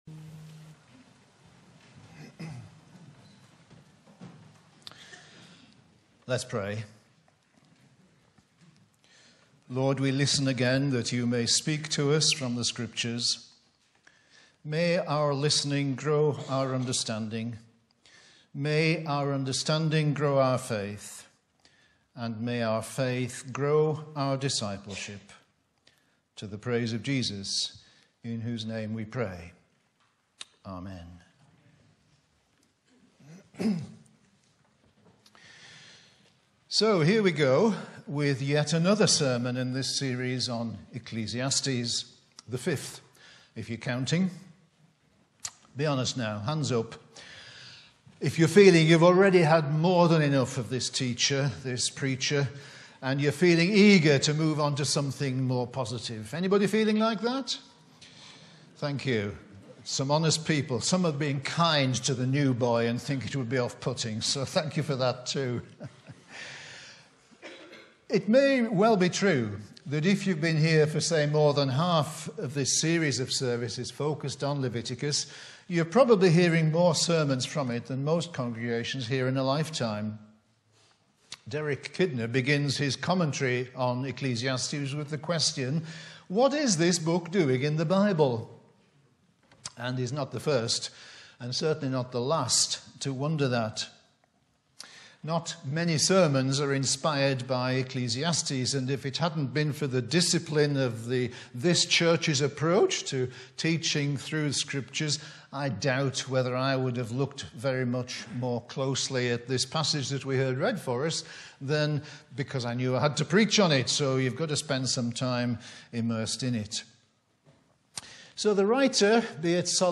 Bible references: Ecclesiastes 5:8-6:12 Location: Brightons Parish Church
Sermon keypoints: - bureaucracy reimagined as responsible citizenship - materialism reimagined as gratitude and generosity - debate reimagined as discernment